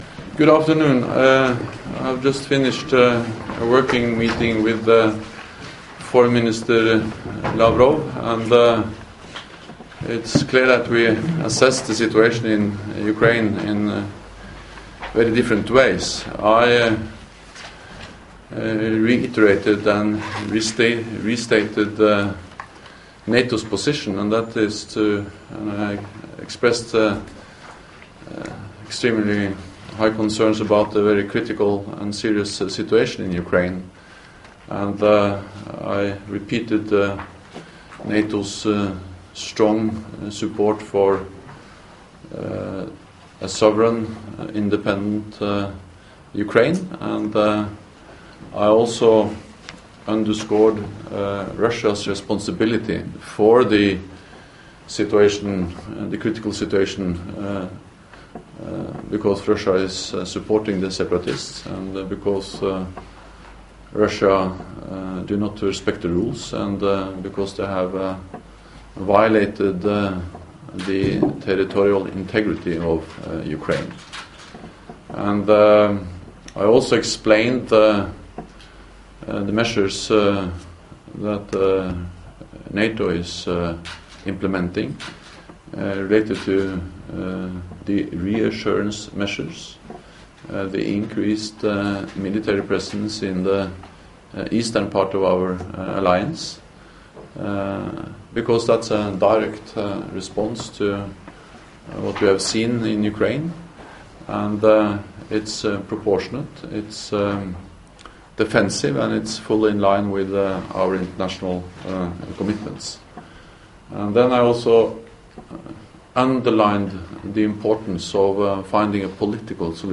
The NATO Secretary General, Jens Stoltenberg, said the efforts by Chancellor Merkel and President Hollande to find a peaceful solution to the Ukraine crisis are ‘important and urgent’. Mr Stoltenberg opened the Munich Security Conference on Friday (6 February 2015) with a keynote speech together with German Defence Minister Ursula von der Leyen and Ambassador Wolfgang Ischinger.